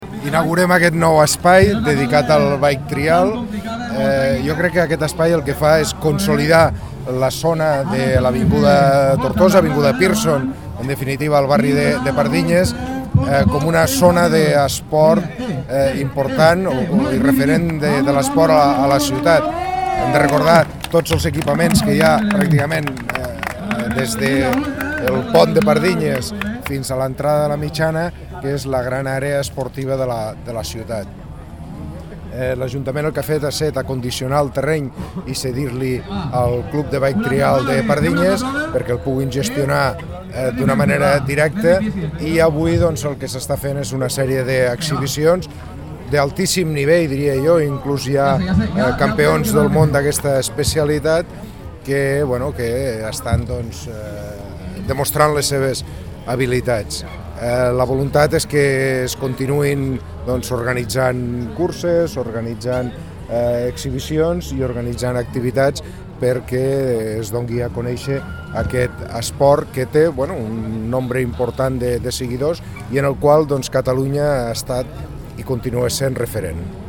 tall-de-veu-del-tinent-dalcalde-i-regidor-de-pardinyes-rafael-peris-sobre-lestrena-del-bike-trial-parc-de-lleida